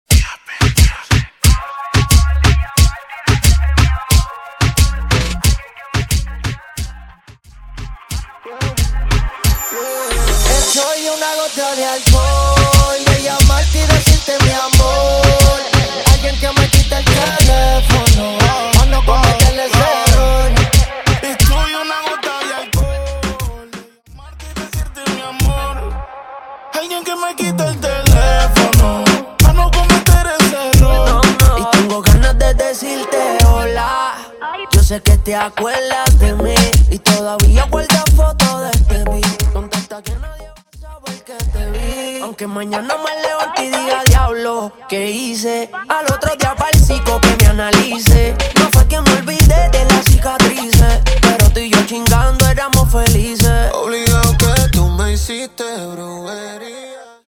Latin genres